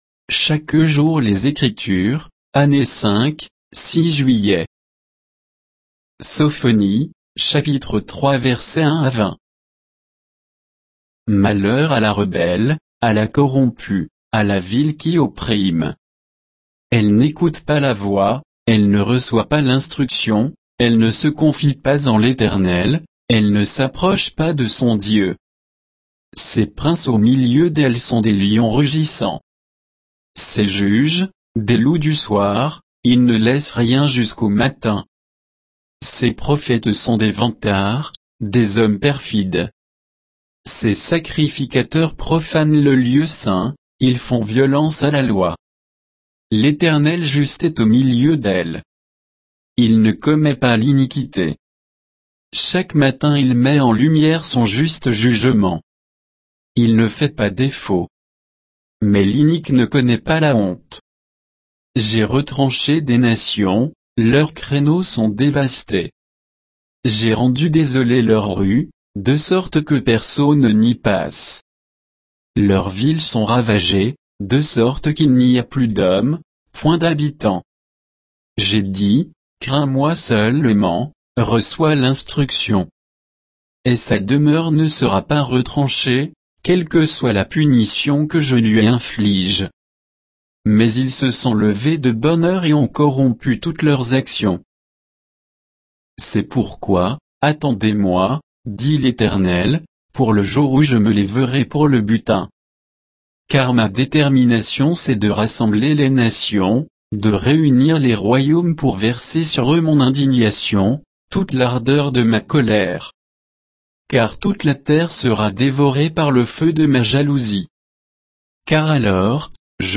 Méditation quoditienne de Chaque jour les Écritures sur Sophonie 3, 1 à 20